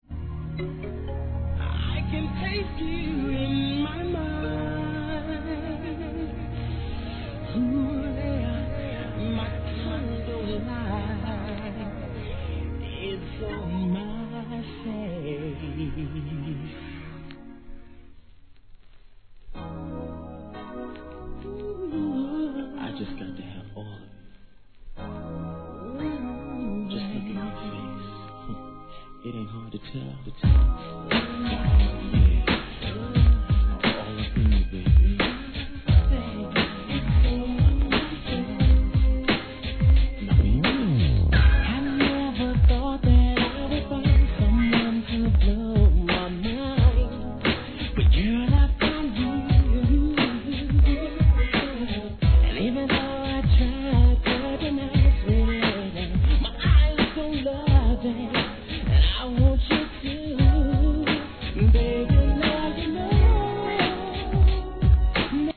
HIP HOP/R&B
バラードから軽快なFUNKまでその圧倒的なセンスは健在!!